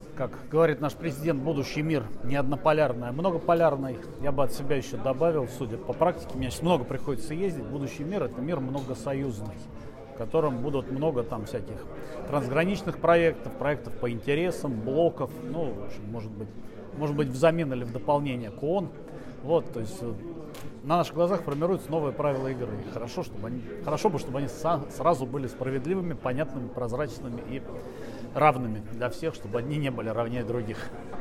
ГЛАВНАЯ > Актуальное интервью
Эксперт участвовал в конференции «Киберстабильность: подходы, перспективы, вызовы», организованной журналом «Международная жизнь».